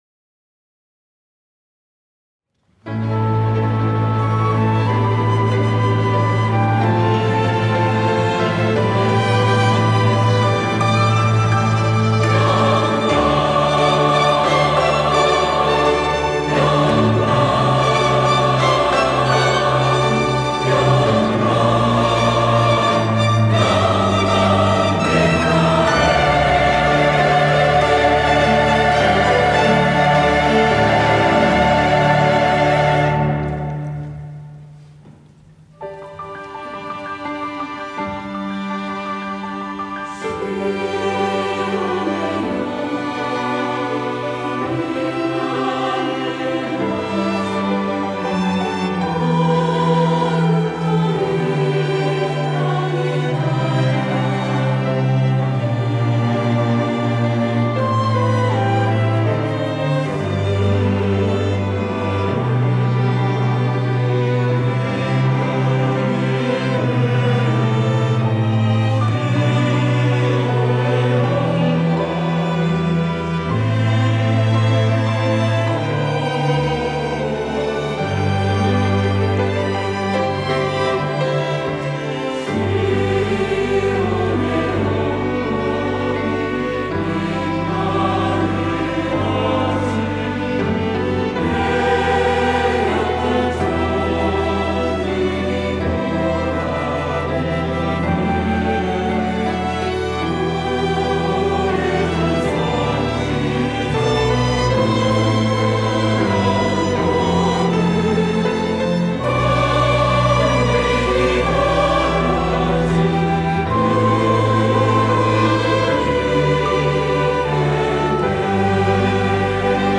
호산나(주일3부) - 시온의 영광이 빛나는 아침
찬양대